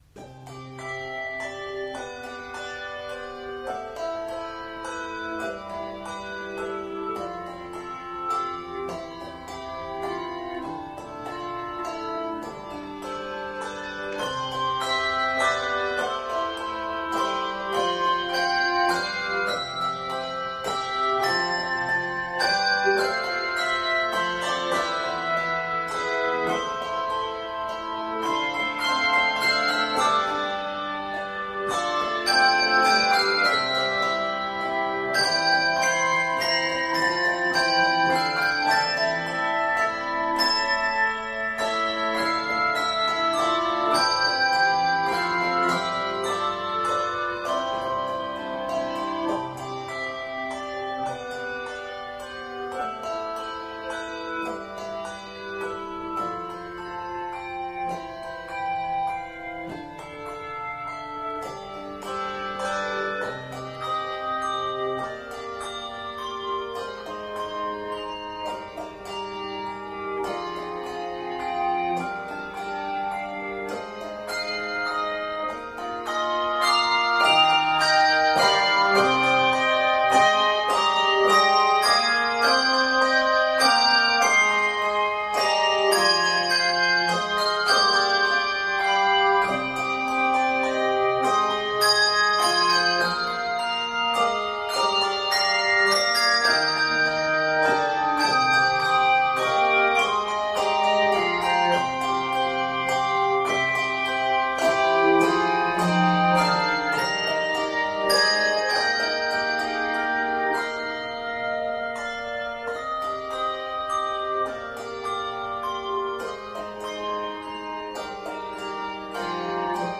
Handbell Choir Schedule and Info